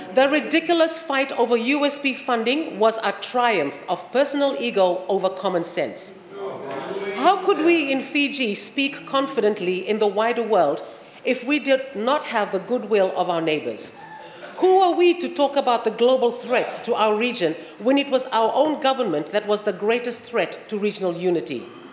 This was a statement made by the Assistant Minister for Foreign Affairs, Lenora Qereqeretabua, in parliament while responding to the 2023–24 budget debate.